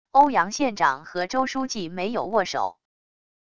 欧阳县长和周书记没有握手wav音频生成系统WAV Audio Player